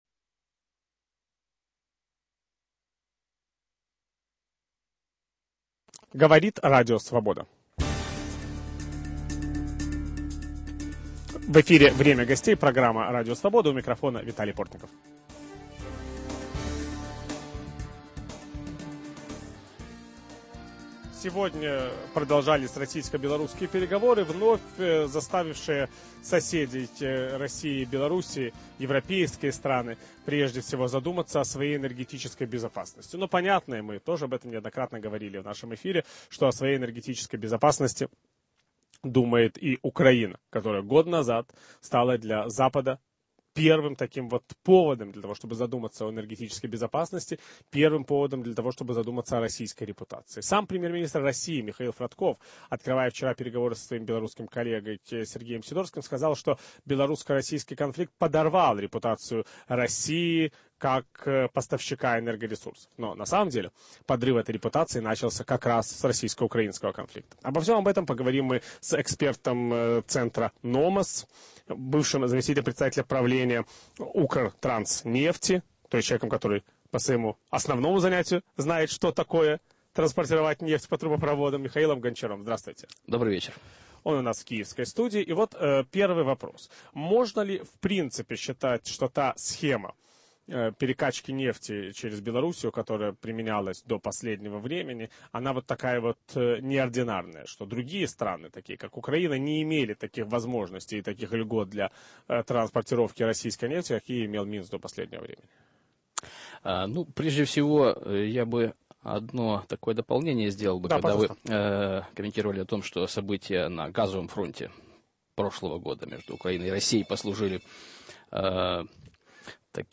Энергетическая безопасность Украины после российско-белорусского конфликта. В киевской студии Радио Свобода